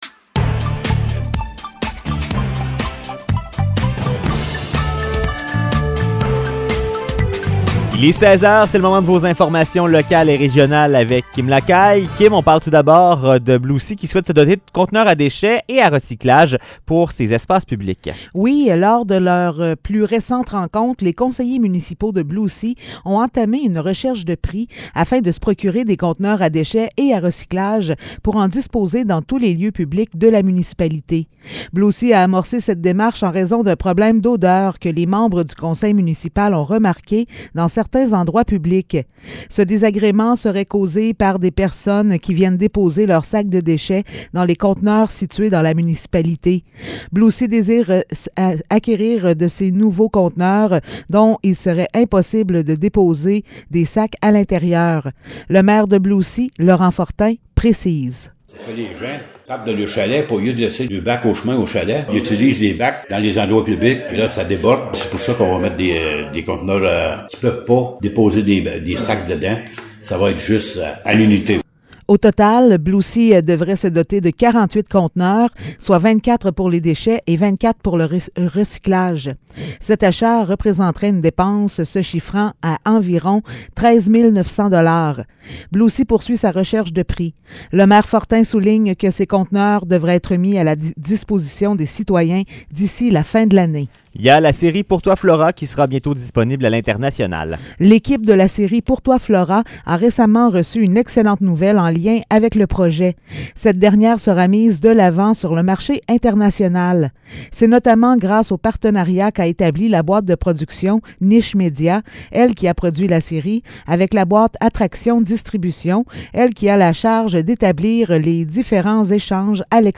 Nouvelles locales – 8 août 2022 – 16 h